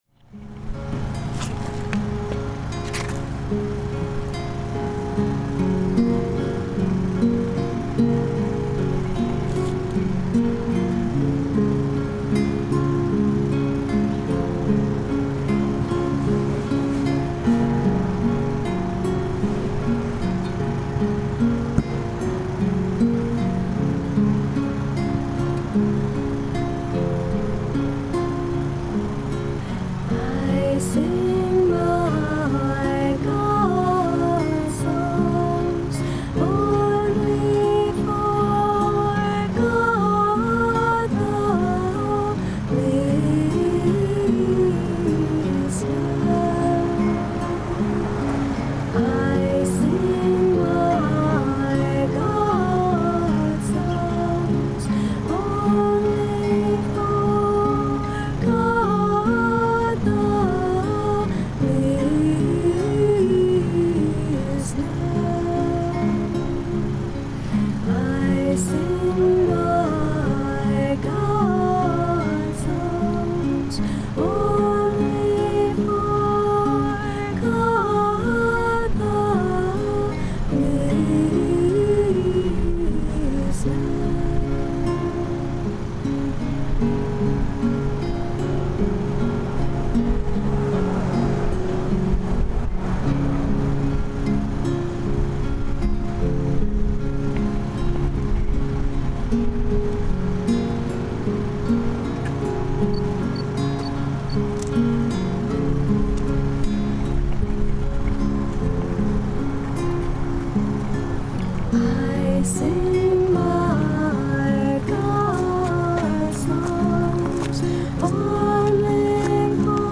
The start of this years Sri Chinmoy Self-Transcendence Race
Musicians
girls-group.mp3